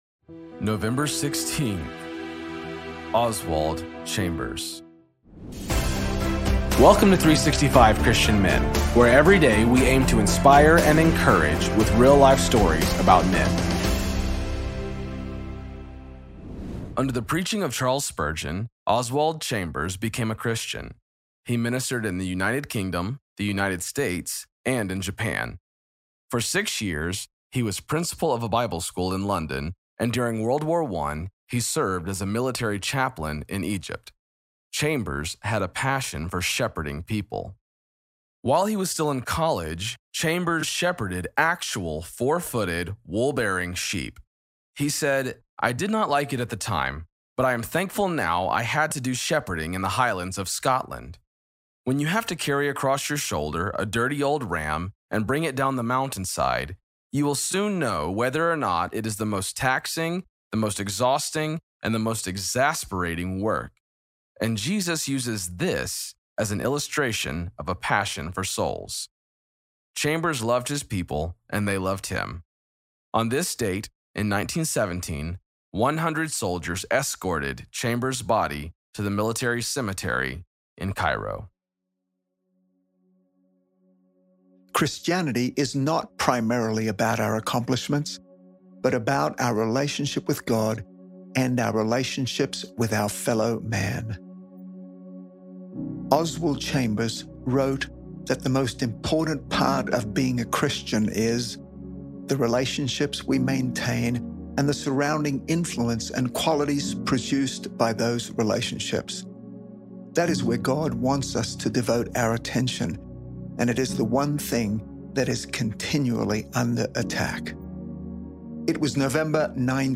Story read by: